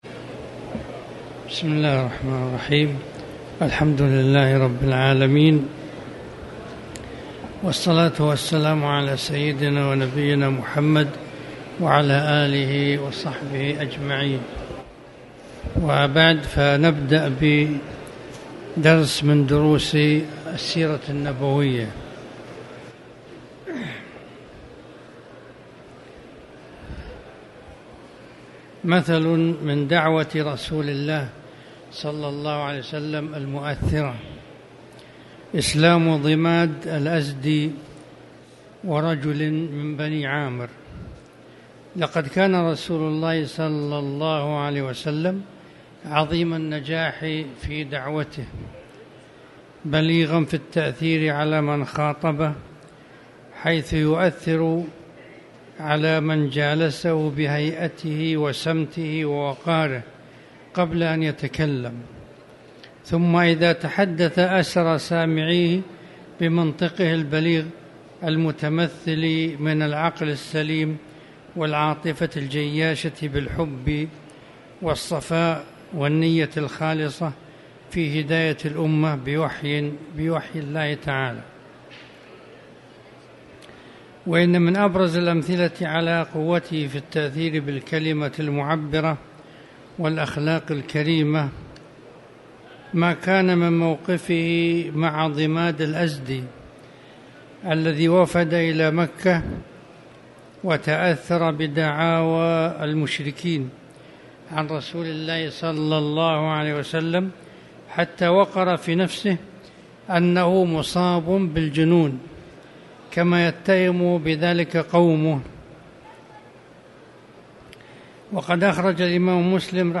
تاريخ النشر ١٥ شوال ١٤٣٨ هـ المكان: المسجد الحرام الشيخ